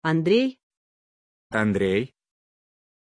Pronuncia di Ondrej
pronunciation-ondrej-ru.mp3